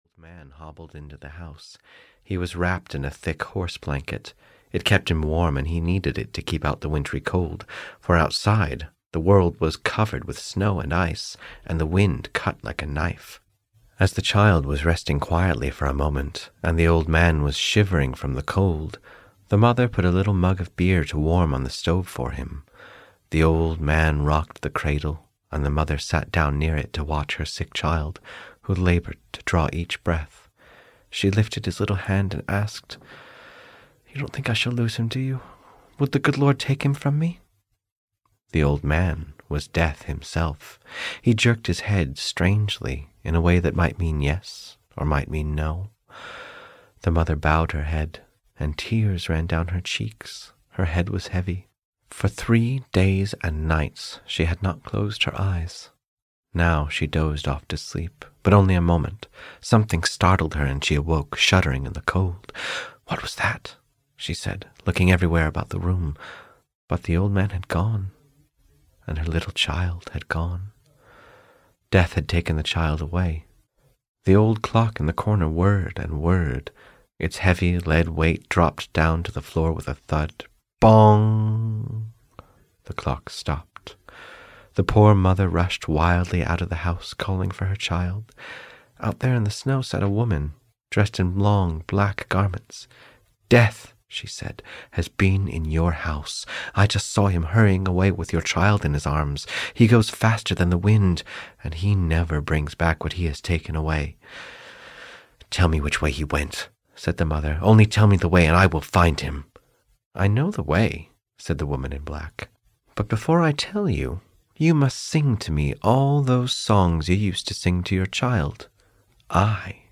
The Story of a Mother (EN) audiokniha
Ukázka z knihy